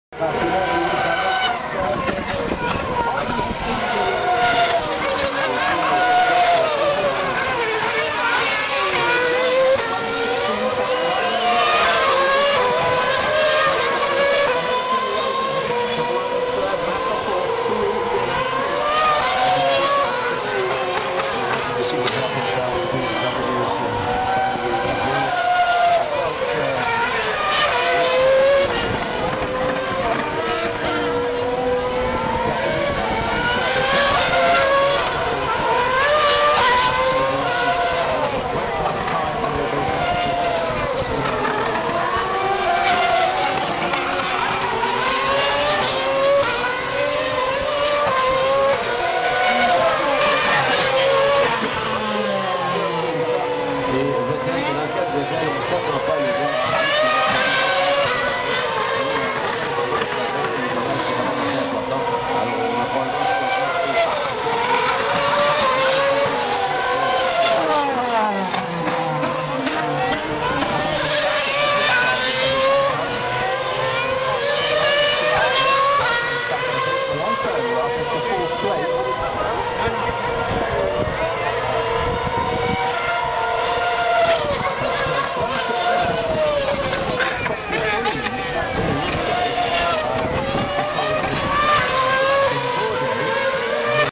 I also have a couple of examples I recorded in Montreal last June of this kind of thing.
Most of the time the crowd is silent, if not quiet. The only voices heard most of the time are from the commentators talking on loudspeakers.
Commentator example.MP3 - 610.2 KB - 359 views